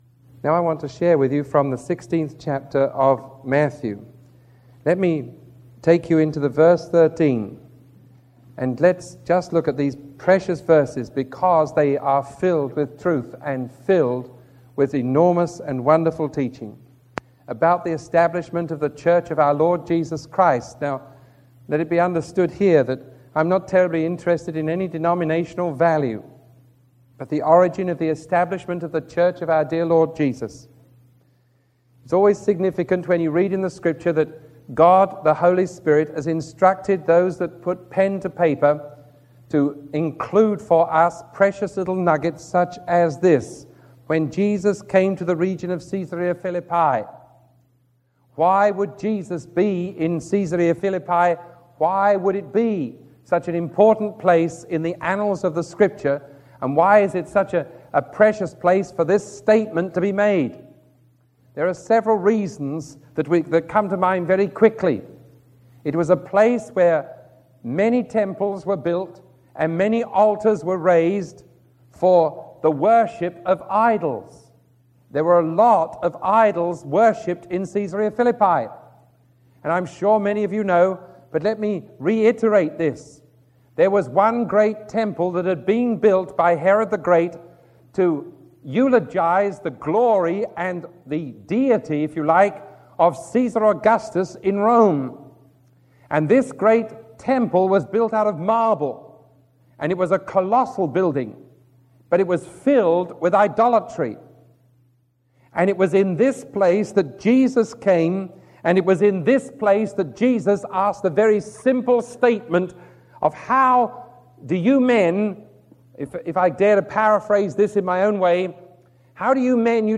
Sermon 1027B recorded on October 13